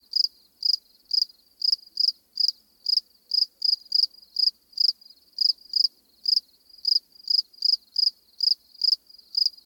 57e0746fe6 Divergent / mods / Soundscape Overhaul / gamedata / sounds / ambient / soundscape / insects / insectday_9.ogg 97 KiB (Stored with Git LFS) Raw History
insectday_9.ogg